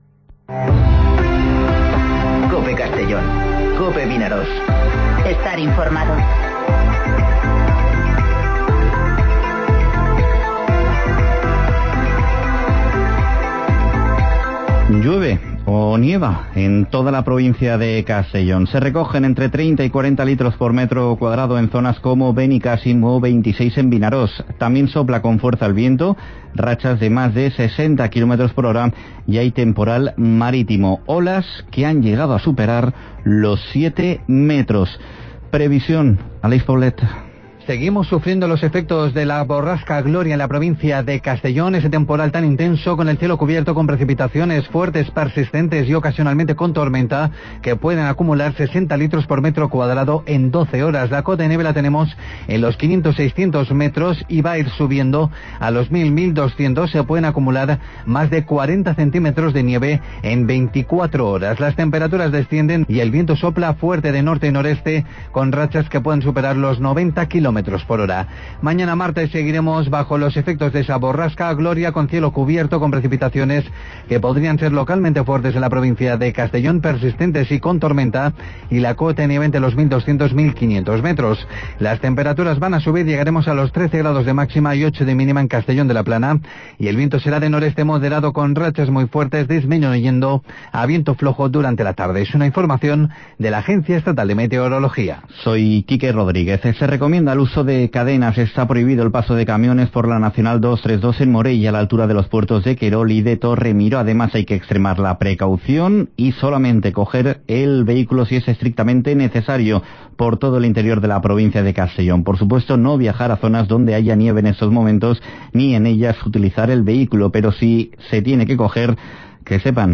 Informativo Mediodía COPE en Castellón (20/01/2020)